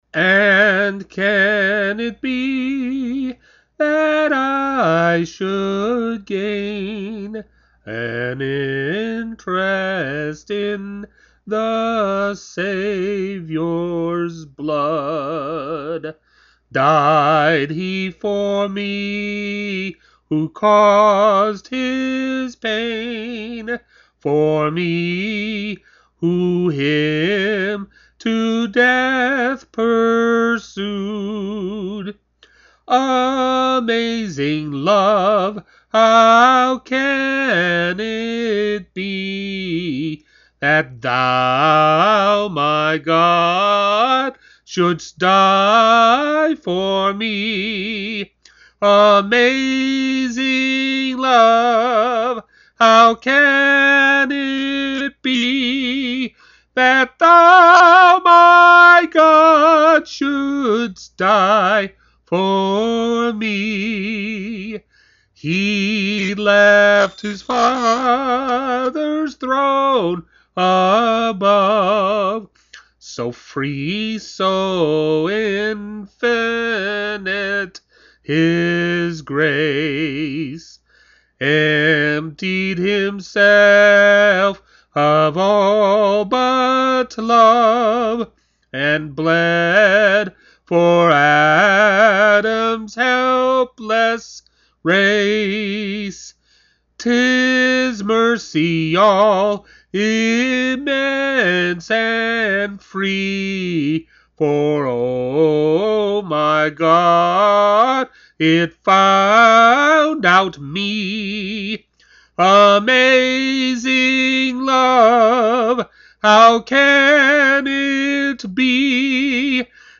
Hymn Singing
Vocal only